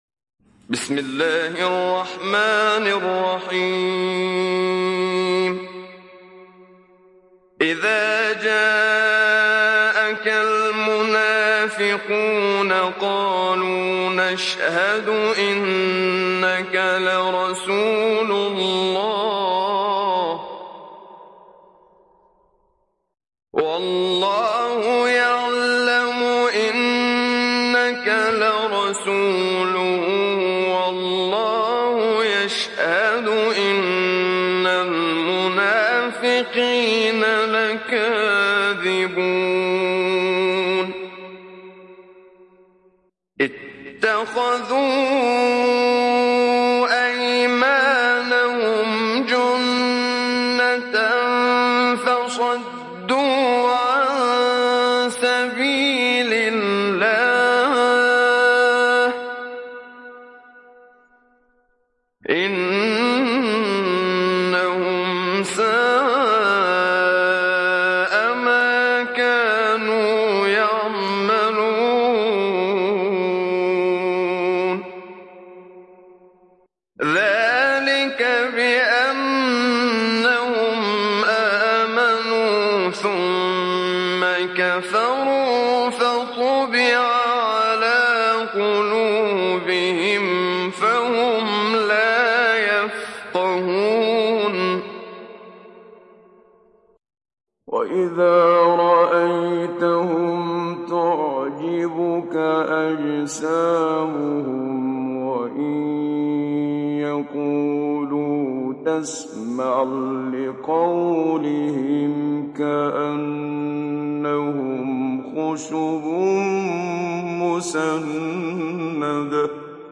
Télécharger Sourate Al Munafiqun Muhammad Siddiq Minshawi Mujawwad